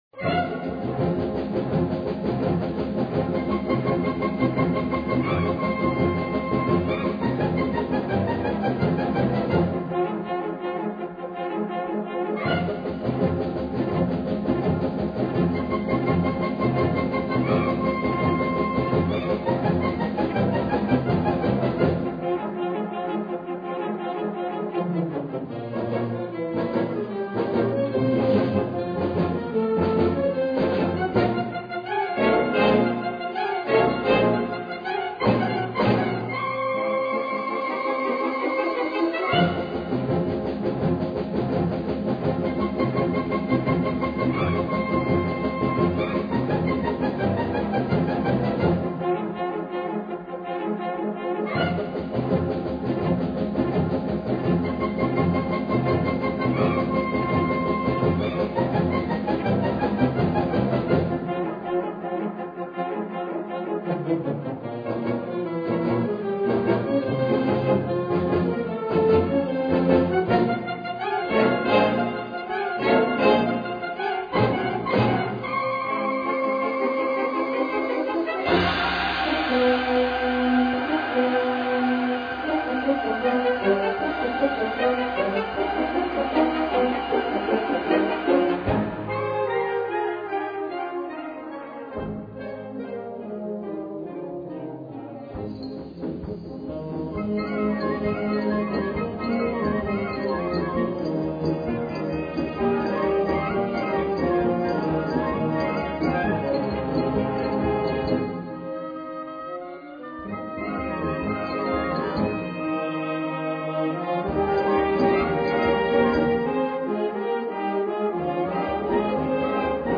Gattung: Konzertante Blasmusik
Besetzung: Blasorchester
Mit einem allgemeinen Tanz (Finale) klingt das Werk aus.